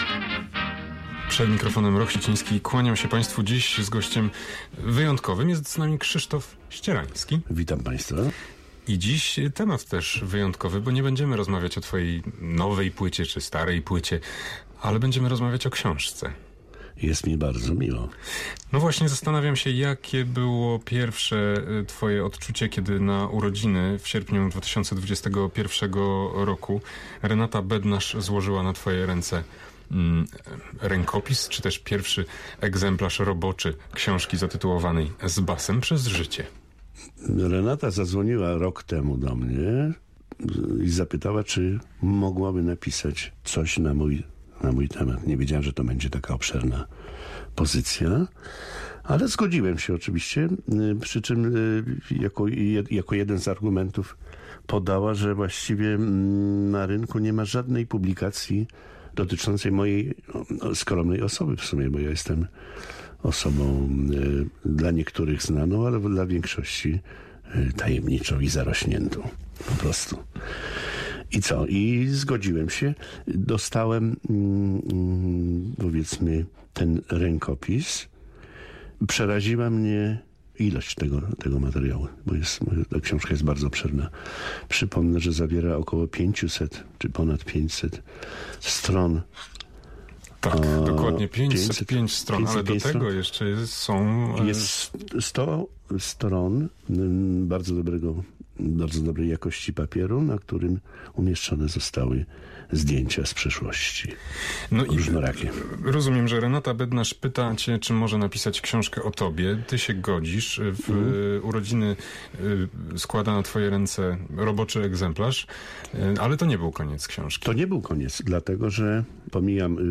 Tej audycji mo�ecie Pa�stwo pos�ucha� tu: Rozmowa o ksi��ce 14.06.2022 Dwie koncertowe atrakcje mamy 17 i 18 czerwca, dzie� po dniu.